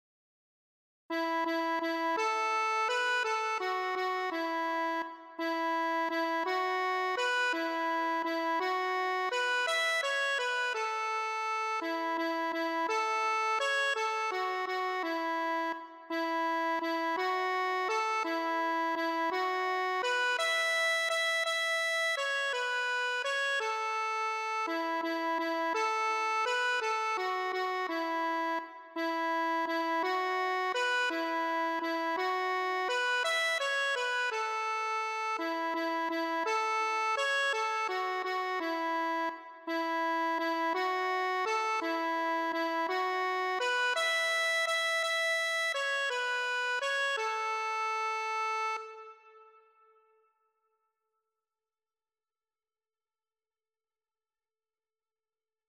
4º Música Flauta, 4º Música Otoño, 4º Música Primavera, 5º Música Flauta, 5º Música Otoño, 5º Música Primavera | 0 Comentarios